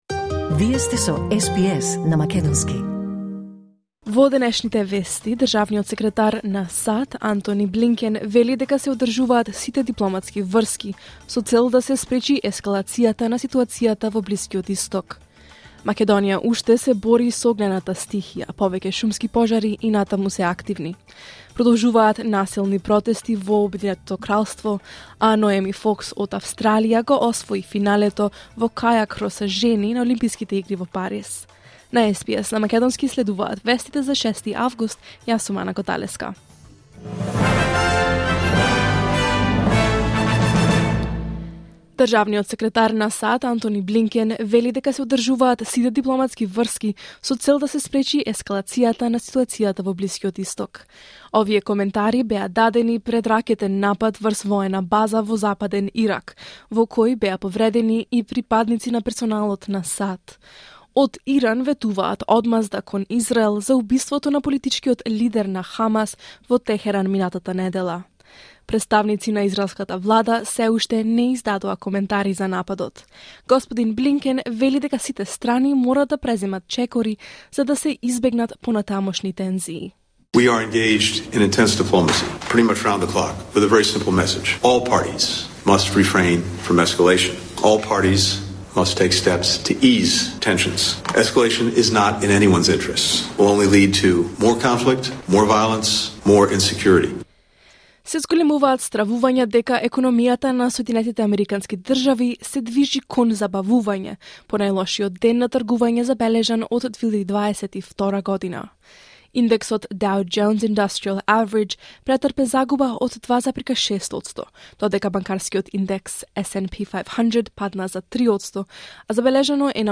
SBS News in Macedonian 6 August 2024